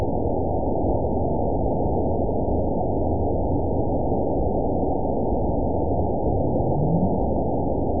event 911050 date 02/08/22 time 23:04:34 GMT (3 years, 3 months ago) score 9.54 location TSS-AB01 detected by nrw target species NRW annotations +NRW Spectrogram: Frequency (kHz) vs. Time (s) audio not available .wav